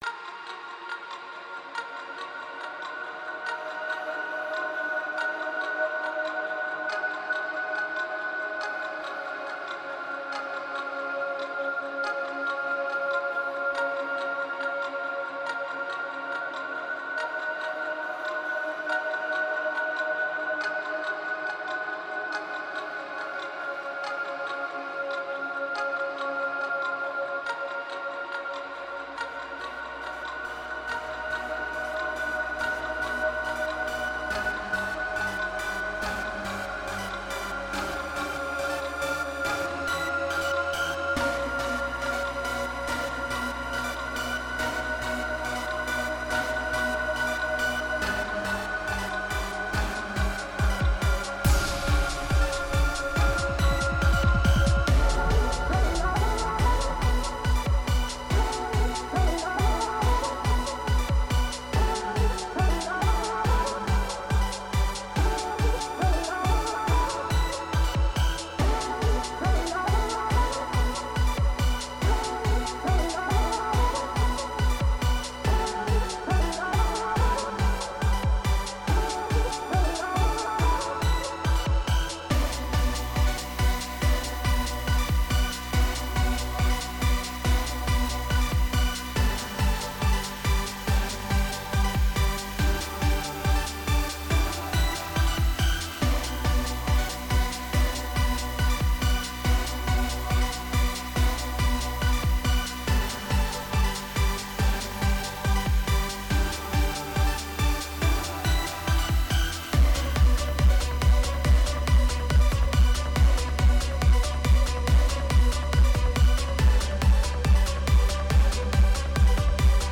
very syncopated trance based track
Tempo 140BPM (Allegro)
Genre Trance
Type adlib-strumental
Mood energetic